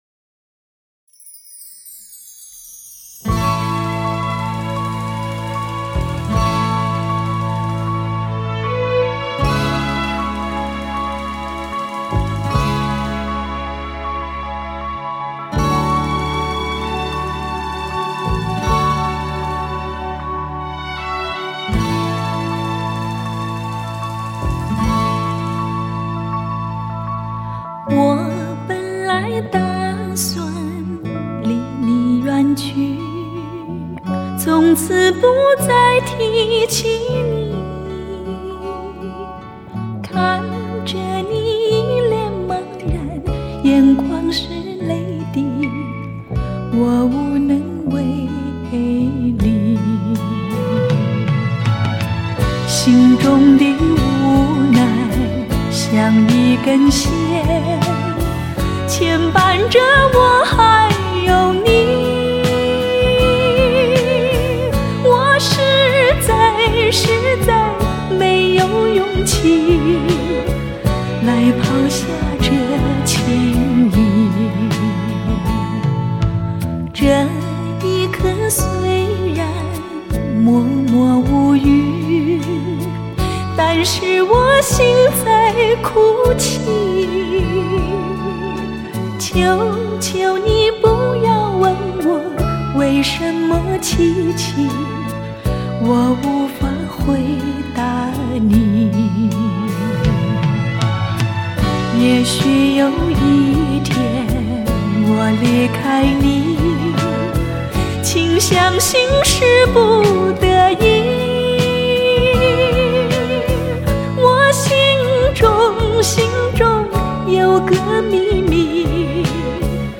その艶やかでいて柔らか、時に母性すら感じる歌唱は珠玉の極みと言えよう。
STEREO/MONO*